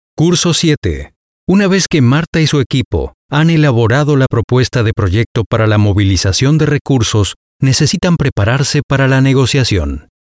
E-learning
Professional Home Studio; Isolated floor and acoustic treatment.
Baritone
WarmConversationalExperiencedReliableFriendly